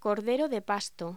Locución: Cordero de pasto
voz